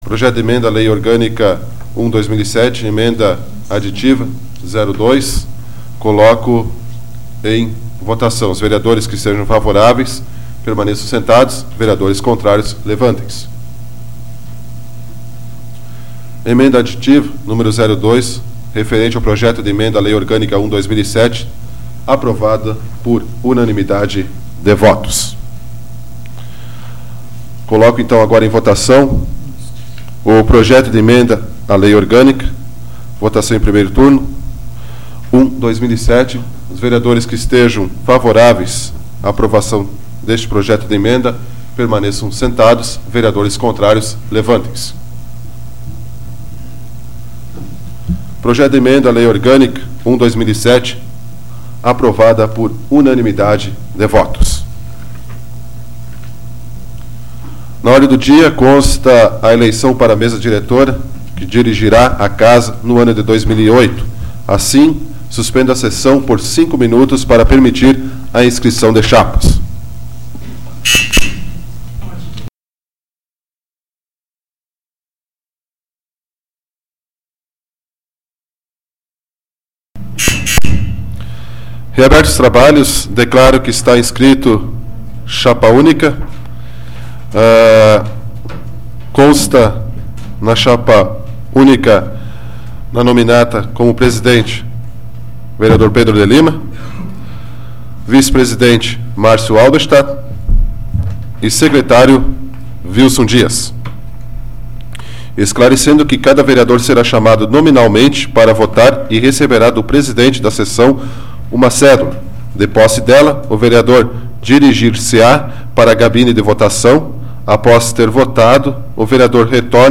Áudio da 44ª Sessão Plenária Extraordinária da 12ª Legislatura, de 26 de dezembro de 2007